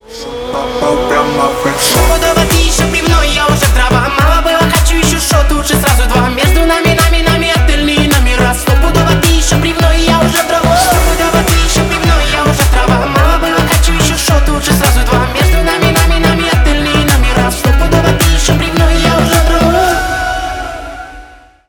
клубные , поп , русские